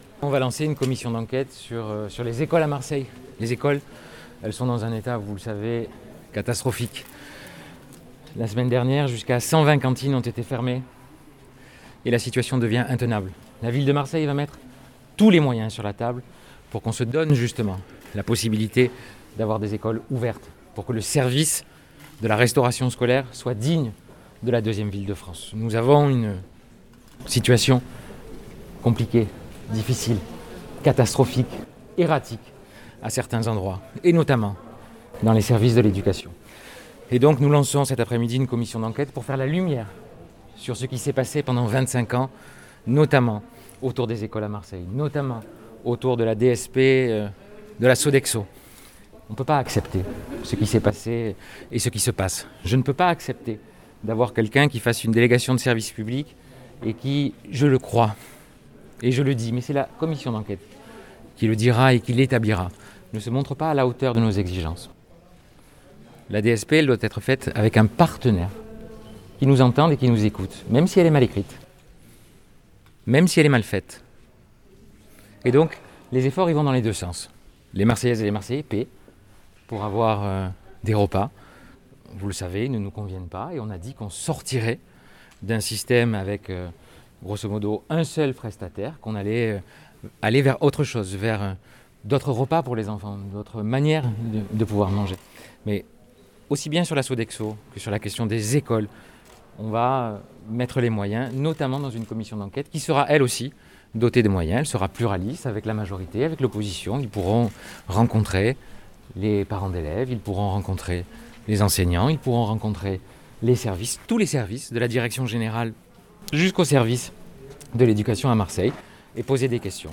Une commission d’enquête lancée, explique l’édile en marge du conseil, «pour faire la lumière sur ce qui s’est passé pendant 25 ans dans les écoles de Marseille notamment autour de la DSP de la Sodexo (Délégation de service public qui arrive à échéance en 2026 NDLR) qui ne se montre pas à la hauteur de nos exigences».